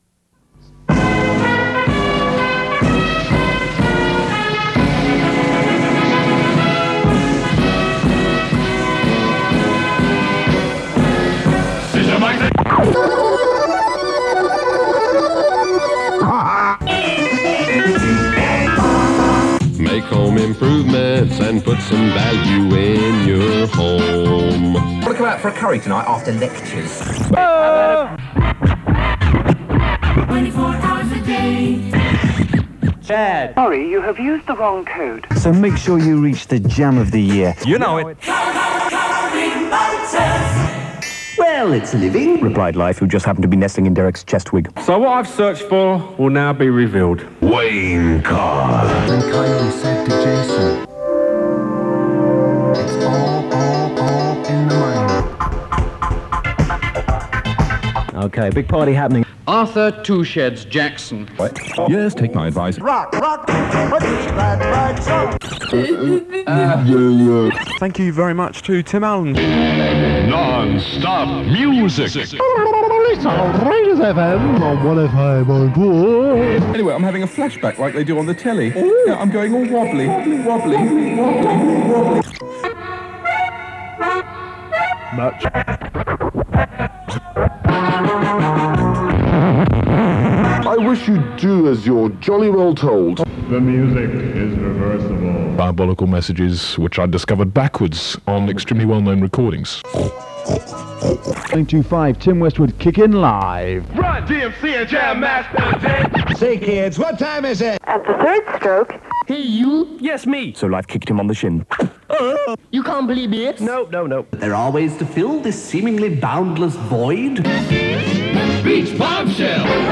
10 minutes of split-millisecond precision pause button punching, this is 'Meanwhile in a Student Canteen, Somewhere...'. It's an early Richcolour Show test transmission, recorded in late 1990 on to an Ampex 472 cassette, deep inside the Pioneer CT-F2121.